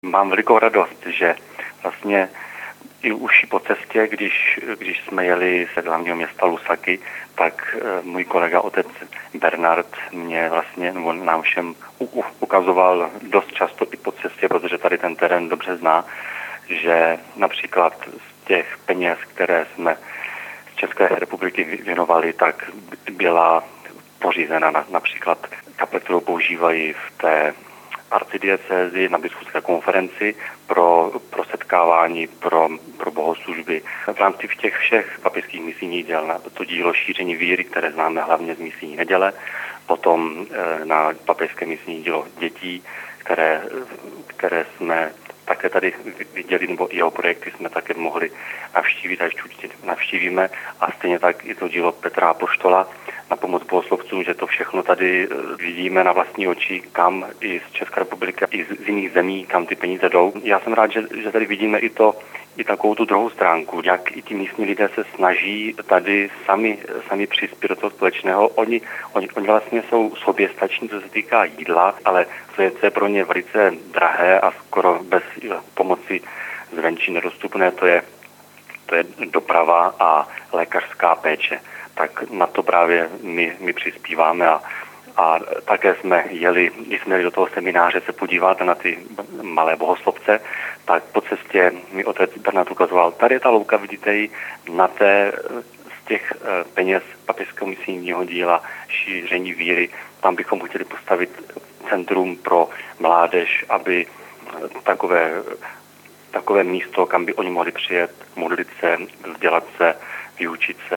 Na začátku týdne jsme se s nimi poprvé telefonicky spojili, do pondělí Svatého týdne jsou hosty v diecézi Chipata na východě země, poblíž hranic s Mosambikem a Malawi.